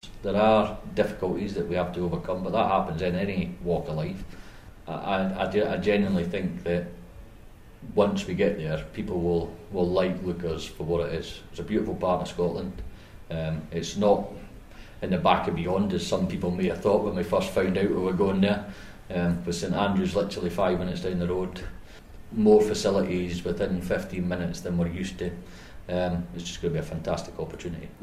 Forth News speaks to soldiers in Germany as they prepare for a big move to Leuchars in Fife.